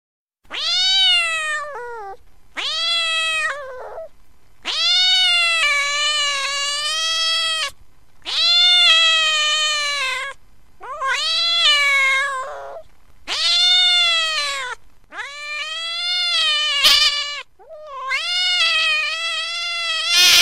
Category: Animal Ringtones